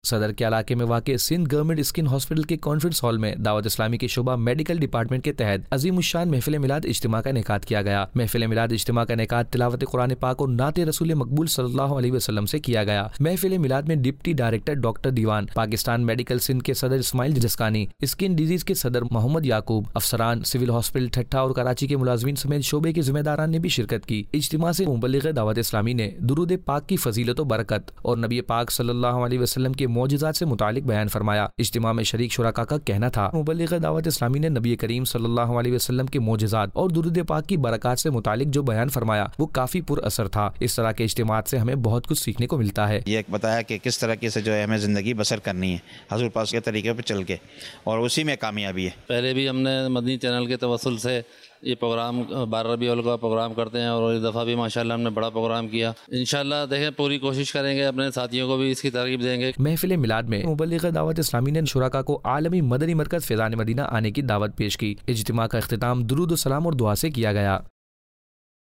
News Clips Urdu - 31 October 2022 - Sindh Government Skin Hospital Kay Conference Hall Main Ijtema Milaad Ka Ineqad Nov 3, 2022 MP3 MP4 MP3 Share نیوز کلپس اردو - 31 اکتوبر 2022 - سندھ گوڑنمنٹ اسکن ہسپتال کے کانفرنس ہال میں اجتماع میلاد کا انعقاد